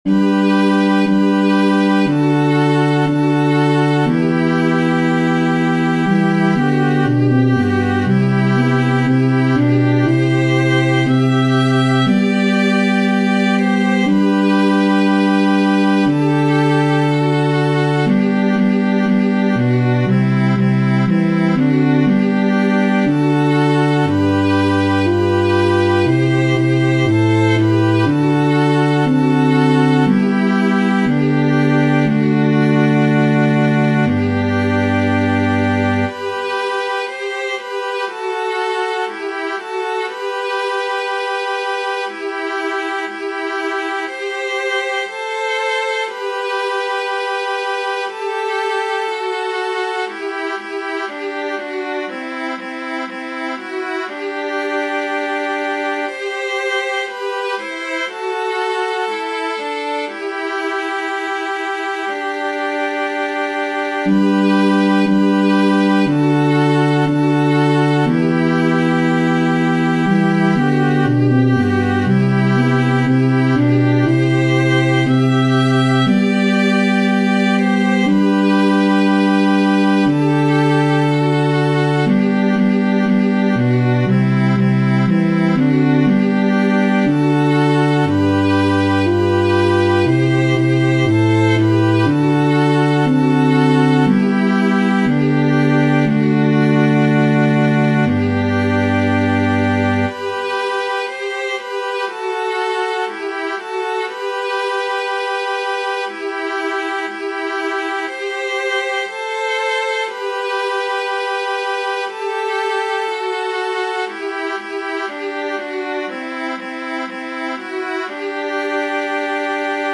Antienne d'ouverture